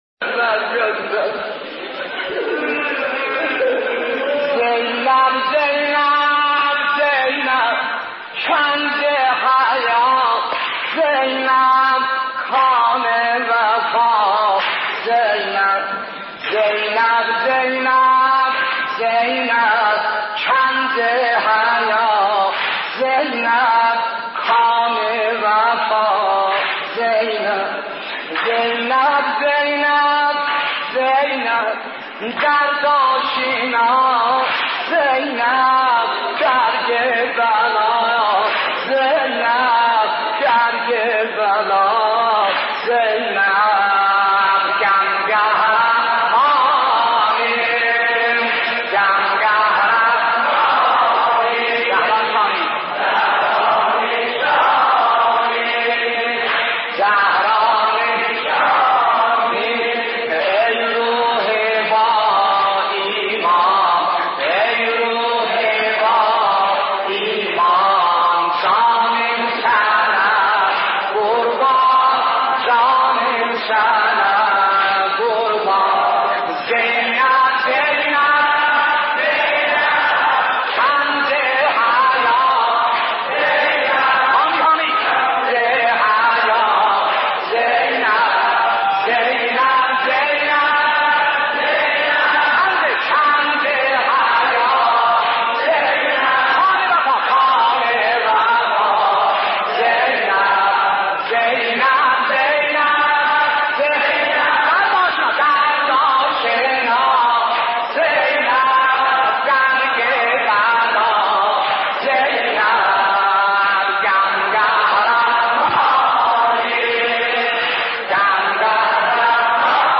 نوحه ترکی زینب زینب با صدای حاج سلیم موذن زاده
مداحی آنلاین - نوحه ترکی زینب زینب با صدای حاج سلیم موذن زاده - مداحی ترکی